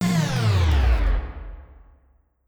snd_guardener_death.wav